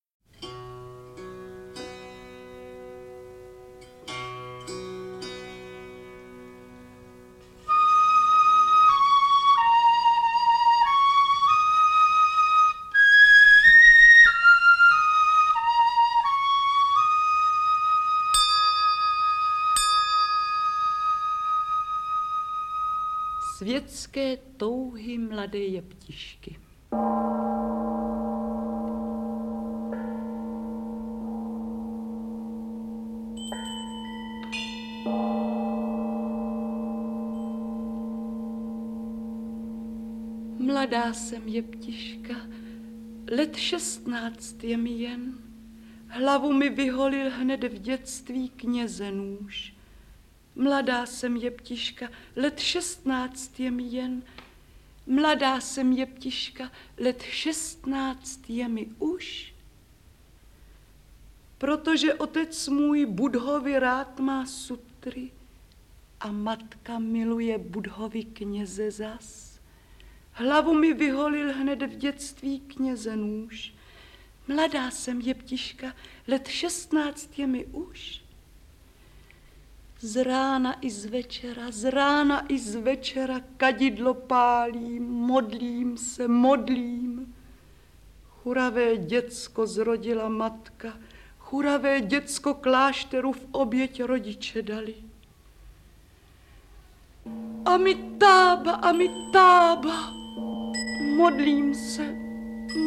Čínská poesie (Světské touhy mladé jeptišky z budhistického dramatu Bílý kožich) - audiokniha obsahuje četbu původní čínské lidové poezie od autorů Li-Po a Tu-Fu. Recituje Marie Burešová a Miloš Nedbal.